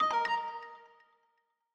Longhorn XP - Print Complete.wav